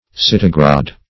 Search Result for " citigradae" : The Collaborative International Dictionary of English v.0.48: Citigradae \Cit`i*gra"d[ae]\, n. pl.